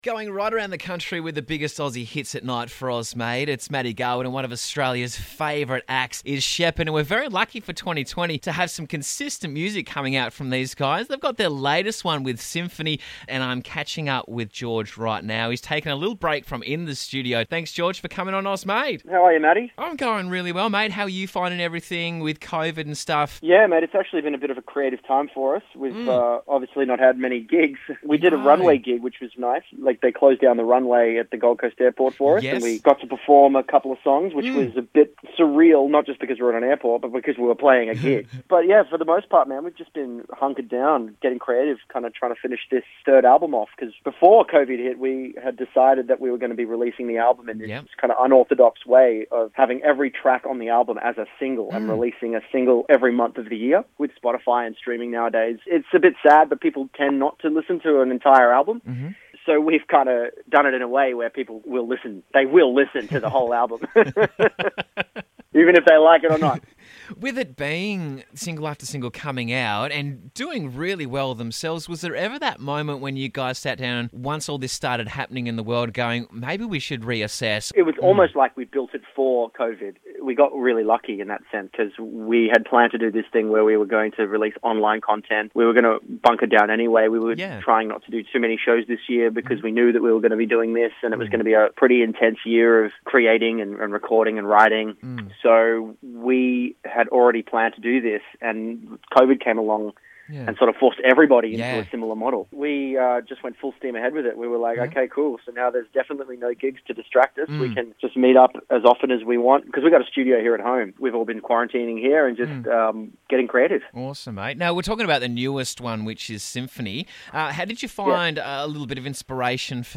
unashamedly joyous pop song